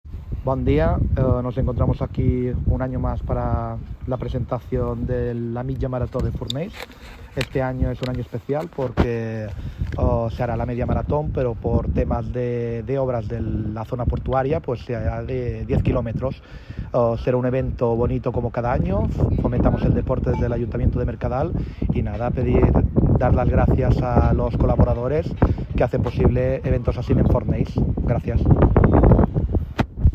Javier Periáñez, regidor de Deportes del Ayuntamiento des Mercadal